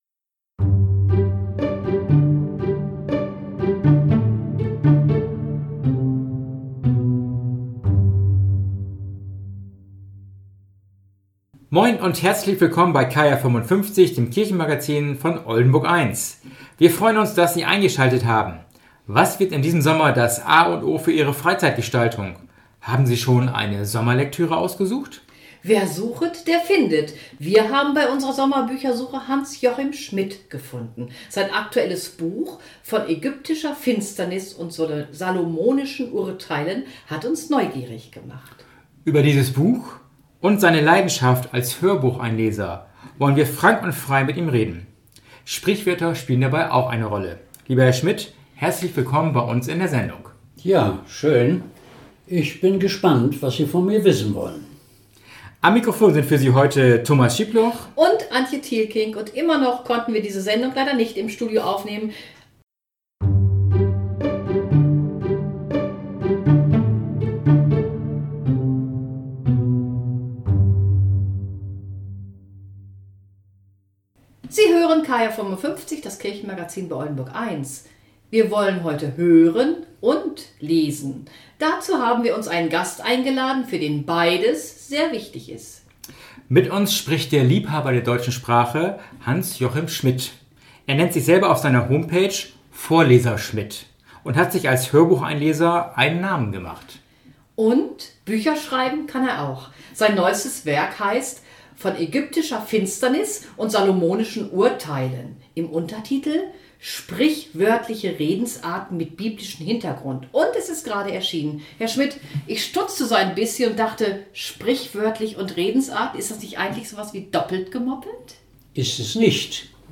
Der geistliche Snack ist in das Gespräch integriert.